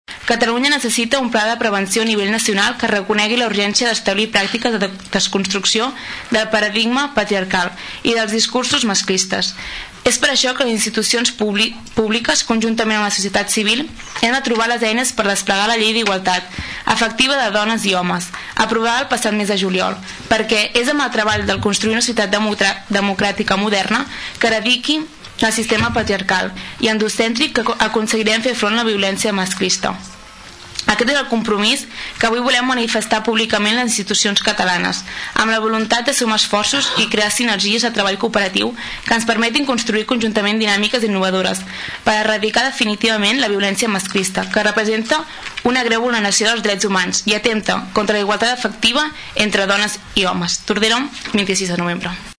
El ple de l’ajuntament de Tordera es suma a la lluita contra la violència de gènere amb la lectura d’un manifest